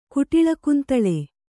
♪ kuṭiḷa kuntaḷe